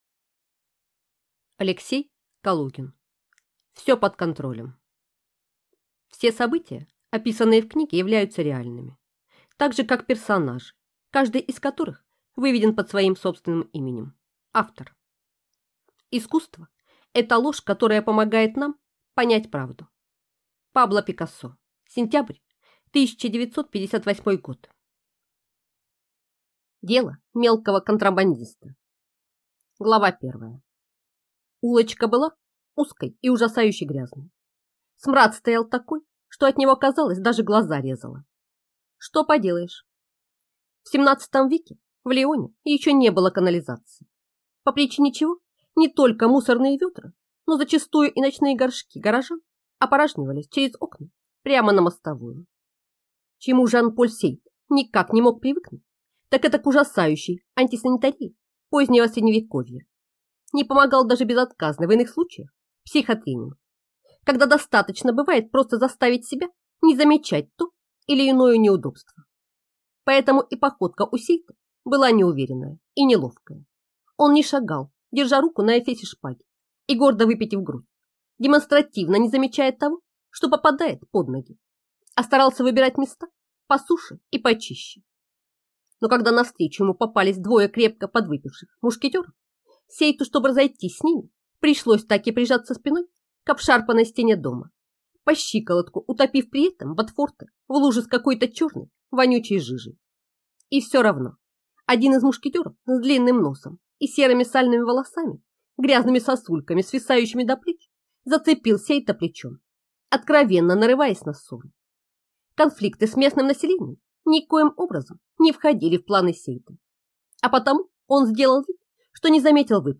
Аудиокнига Все под контролем (Сборник) | Библиотека аудиокниг